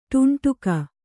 ♪ ṭunṭuka